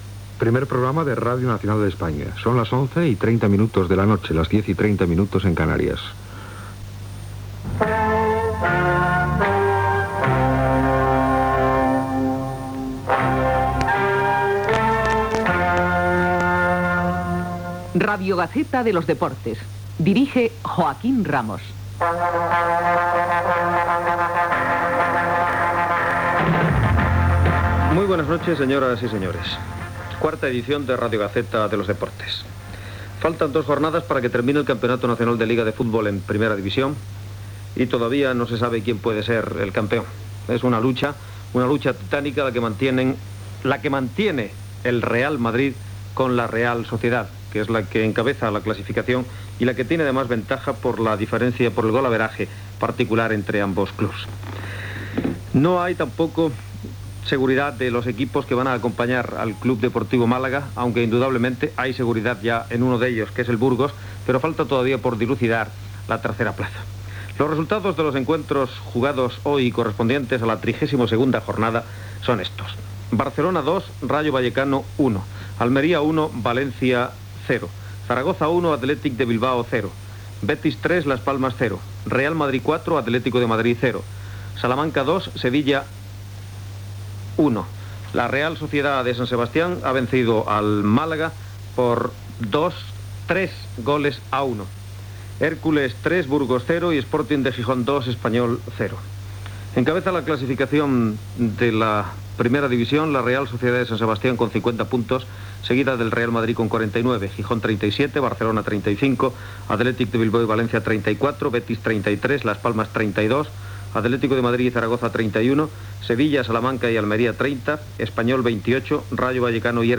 Identificació com Primer Programa de Radio Nacional, careta del programa, la classificació de la primera divisió de la Lliga de futbol masculí a dues jornades del final.
Esportiu